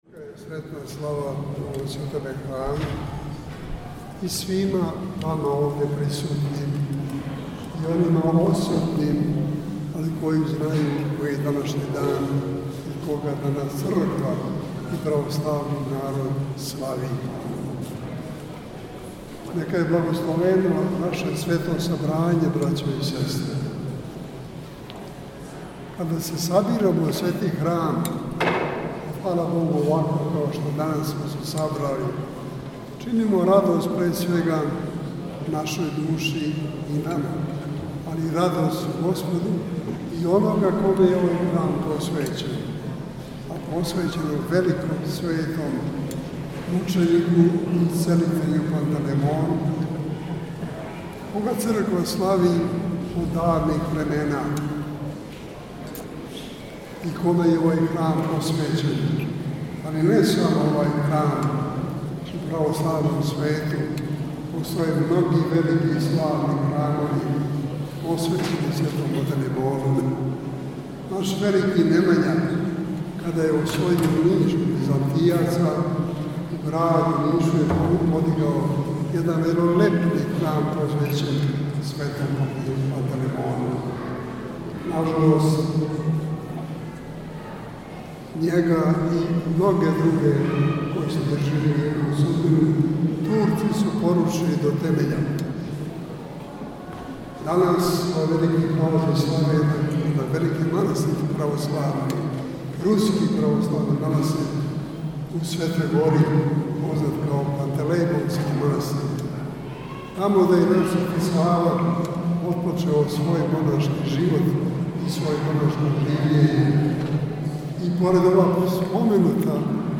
Његова Светост Патријарх српски г. Иринеј началствовао је 9. августа 2020. године, поводом храмовне славе, светом архијерејском Литургијом у цркви Светог великомученика Пантелејмона у Миријеву.